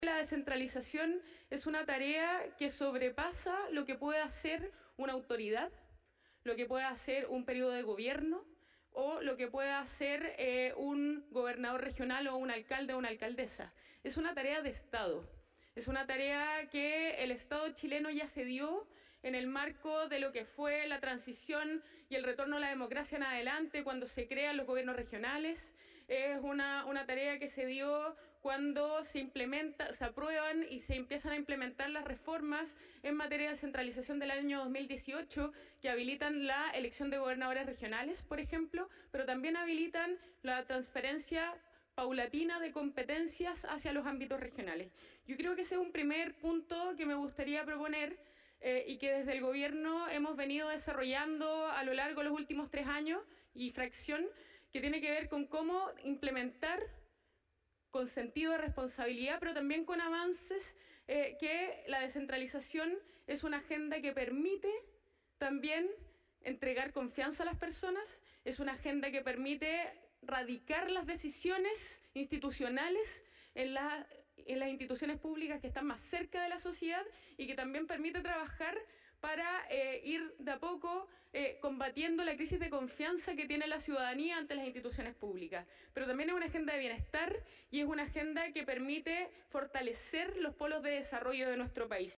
“Descentralización para avanzar” fue el lema de la Cumbre de las Regiones 2025, realizada este lunes en el Teatro Biobío y organizada conjuntamente por Corbiobío, el Gobierno Regional, Desarrolla Biobío y la Asociación de Gobernadores y Gobernadoras Regionales de Chile (Agorechi).